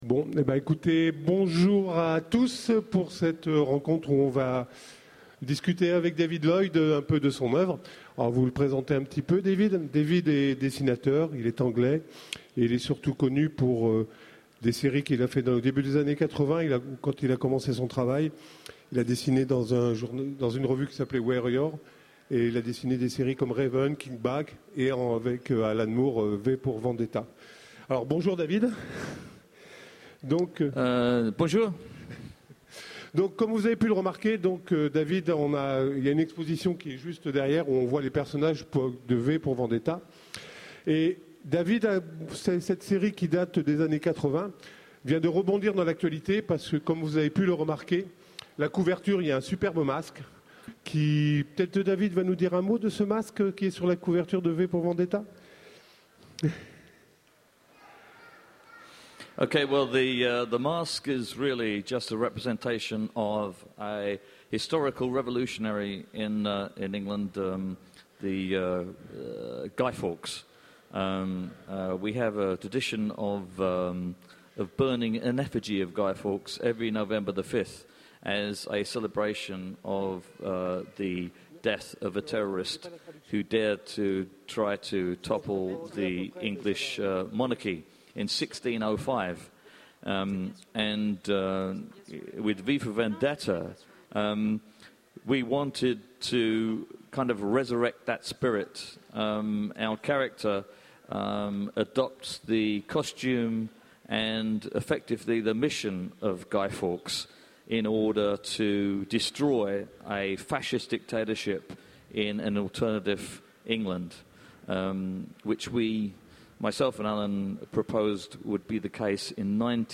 Utopiales 2011 : Conférence Rencontre avec David Lloyd 2
- le 31/10/2017 Partager Commenter Utopiales 2011 : Conférence Rencontre avec David Lloyd 2 Télécharger le MP3 à lire aussi David Lloyd Genres / Mots-clés Rencontre avec un auteur Conférence Partager cet article